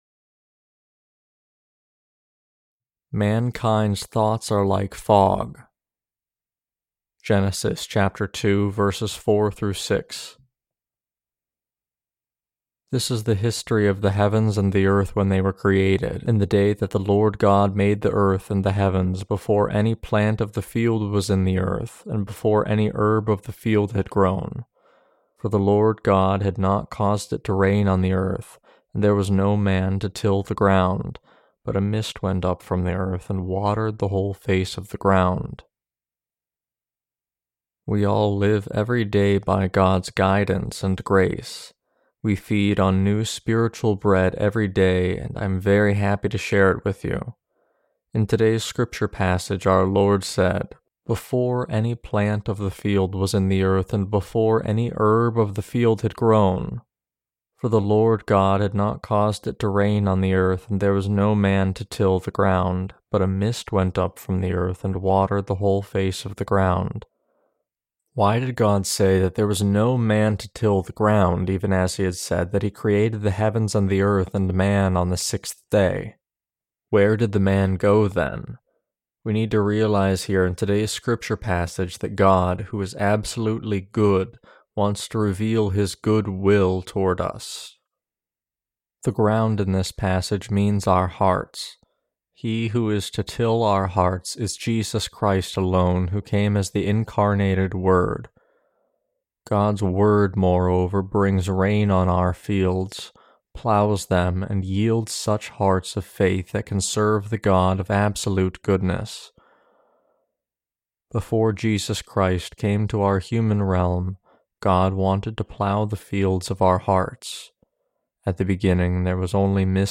Sermons on Genesis (II) - The Fall of Man and The Perfect Salvation of God Ch2-2.